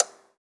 pgs/Assets/Audio/Miscellaneous/Clock/clock_tick_01.wav
clock_tick_01.wav